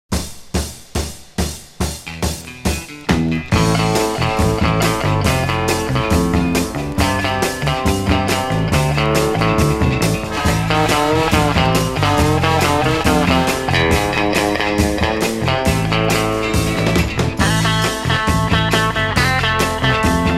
(税込￥3080)   INST